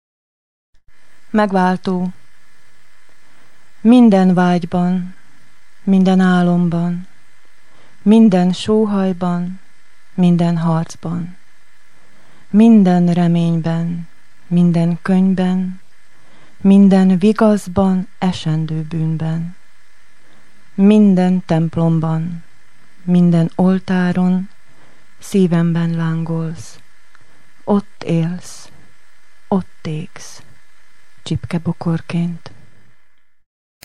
Versmondók